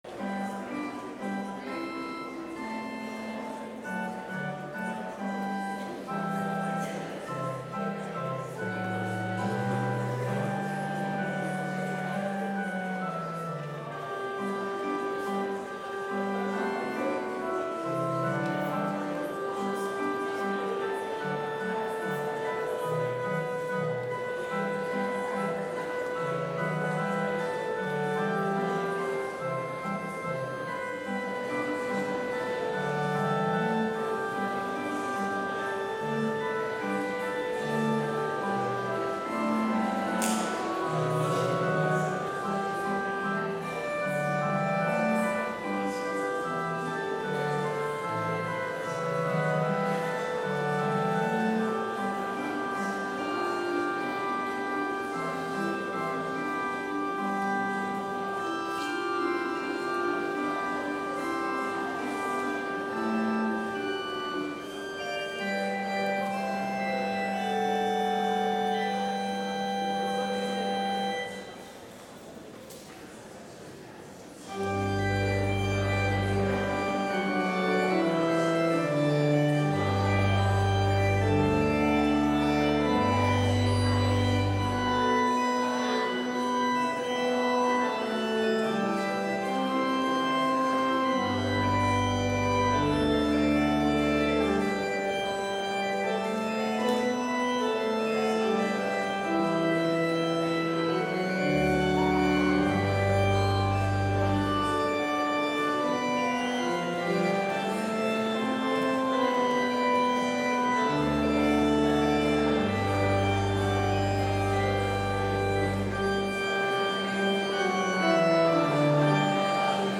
Complete service audio for Chapel - September 16, 2021